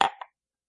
烹饪 " 放杯1
Tag: 厨房 桌子 午餐杯